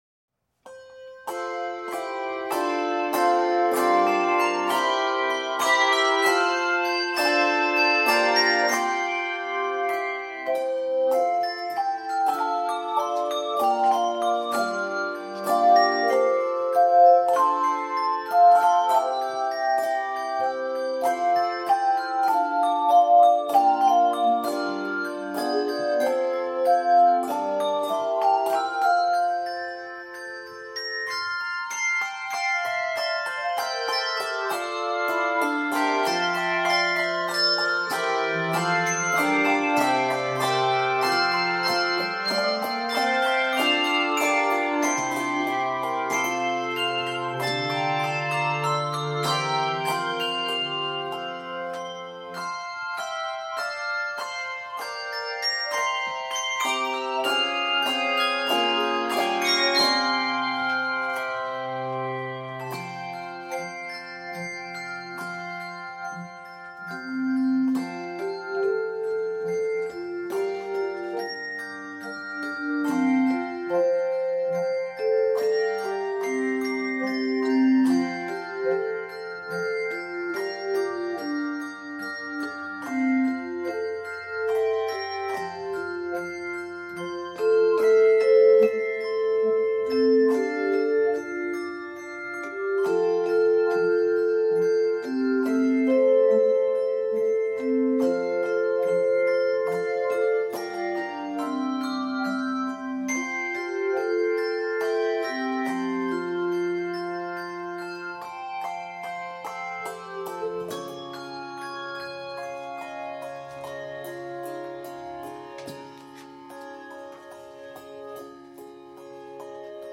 is a beautiful, flowing arrangement